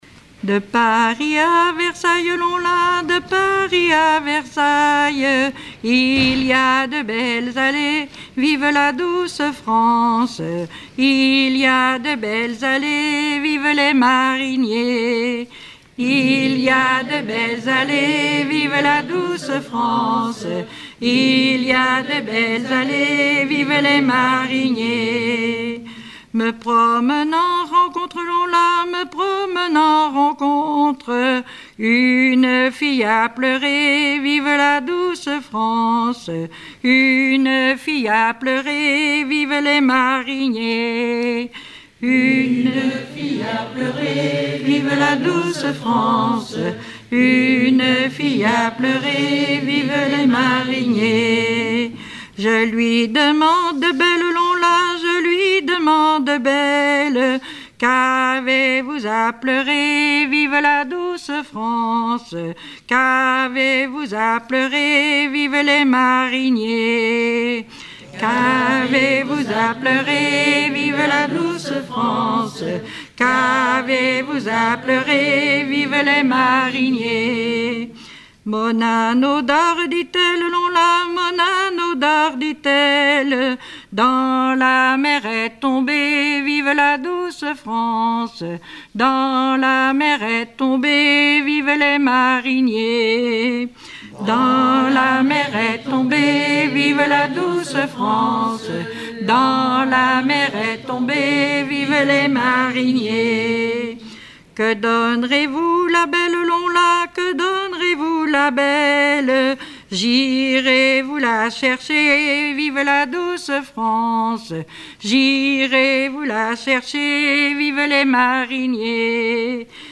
Chansons traditionnelles et populaires